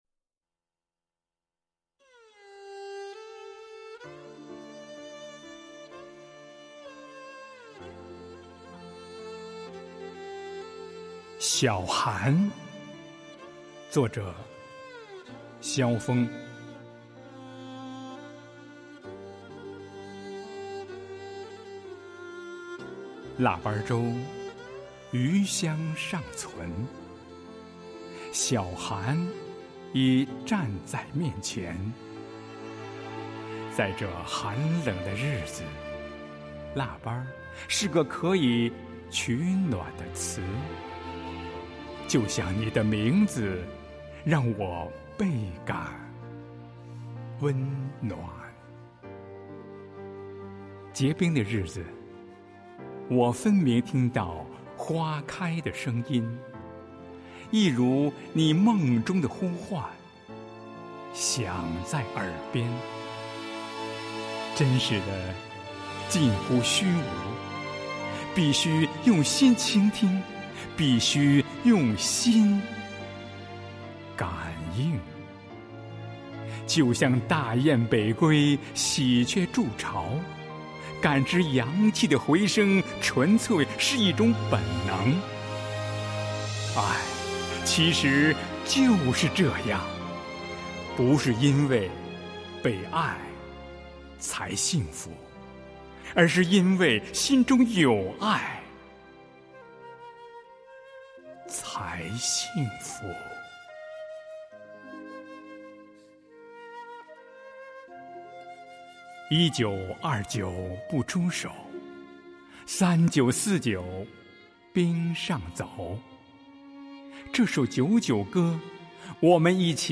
读诗的人